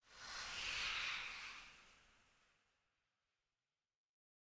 Minecraft Version Minecraft Version snapshot Latest Release | Latest Snapshot snapshot / assets / minecraft / sounds / block / dried_ghast / ambient1.ogg Compare With Compare With Latest Release | Latest Snapshot